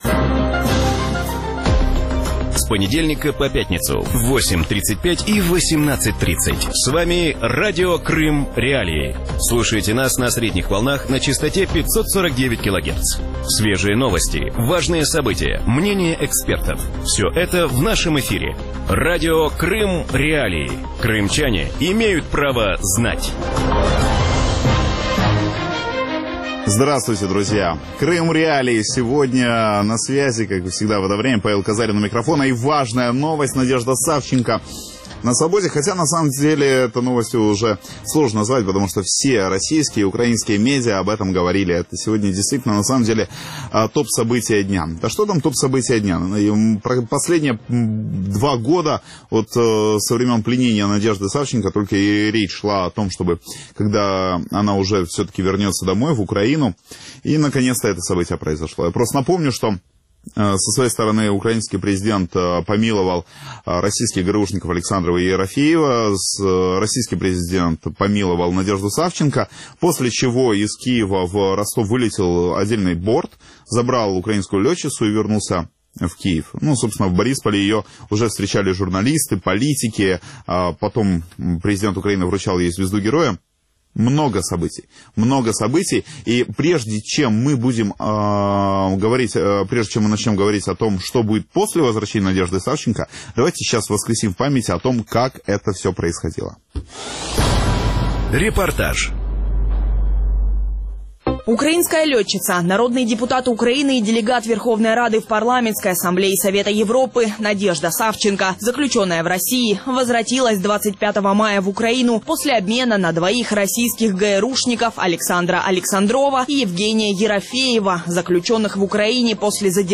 Ответ на этот вопрос искали вместе с экспертами в эфире Радио Крым.Реалии